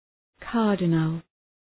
Shkrimi fonetik {‘kɑ:rdınəl}